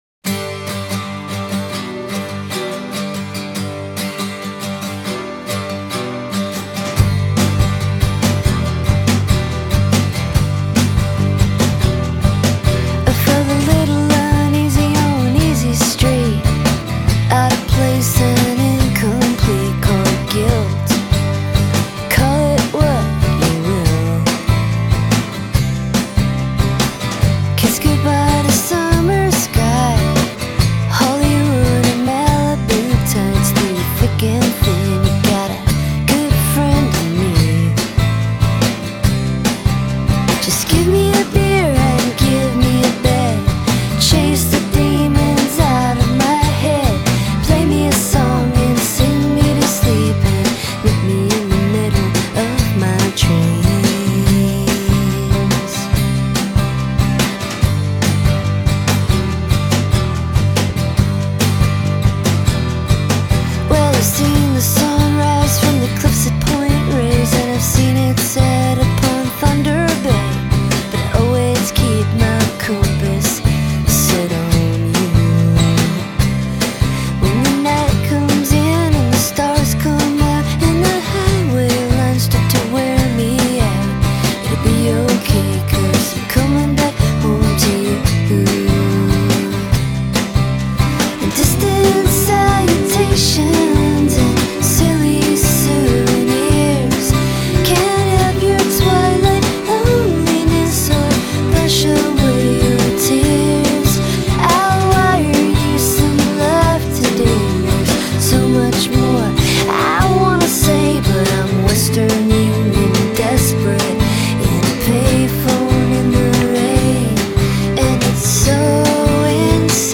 acoustic swing